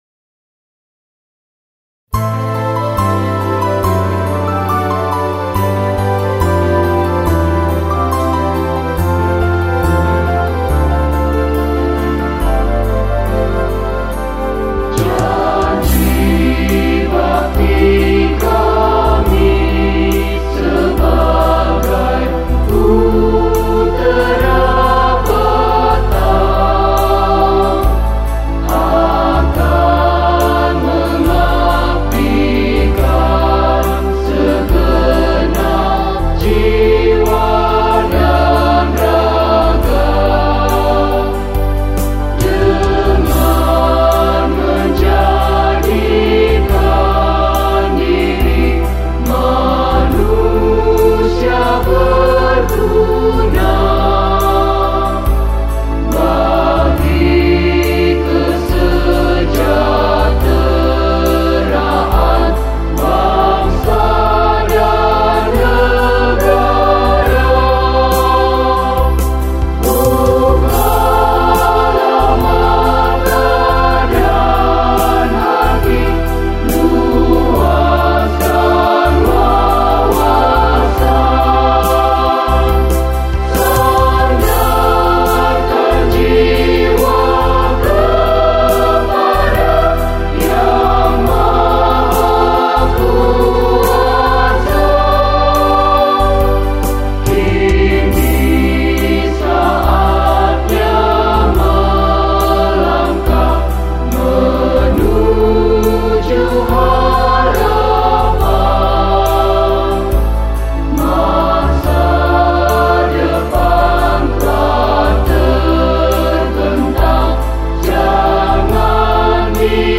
Hymne_Putera_Batam.mp3